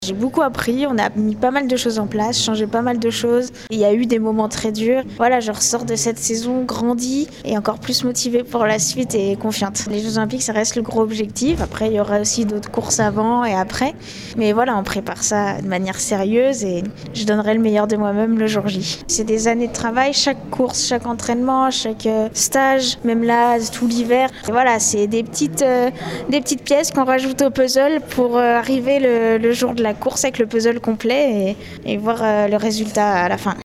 Loana Lecomte nous dresse un bilan de sa saison et évoque les Jeux Olympiques de Paris l’an prochain: